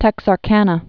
(tĕksär-kănə)